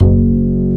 Index of /90_sSampleCDs/Roland - Rhythm Section/BS _Jazz Bass/BS _Acoustic Bs